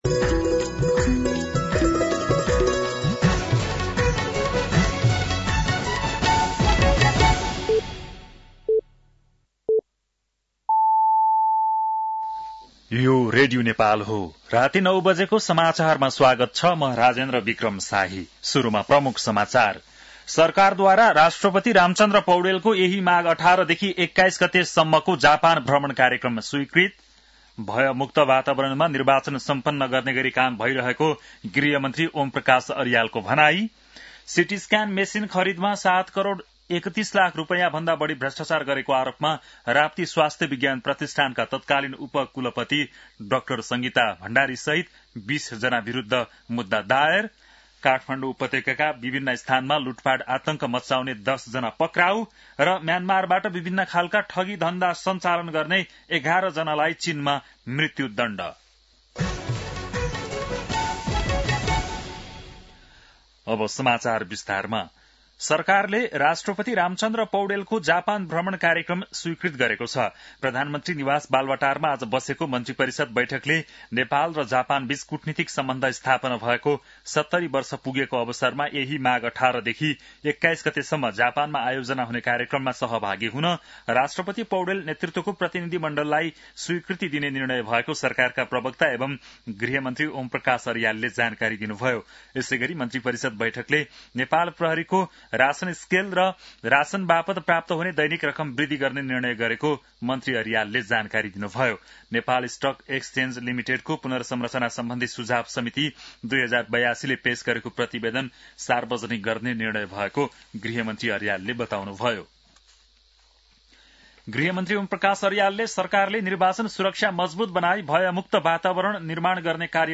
बेलुकी ९ बजेको नेपाली समाचार : १५ माघ , २०८२
9-PM-Nepali-NEWS-1-4.mp3